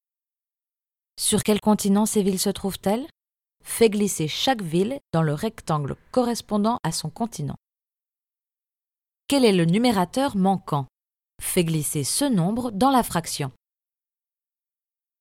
Sprecherin französisch, tiefe Stimme, Werbung, Anrufbeantworter, ProTools
Sprechprobe: eLearning (Muttersprache):
Voice over, deep, narrator, commercials, IVR, smiley, peaceful, Protools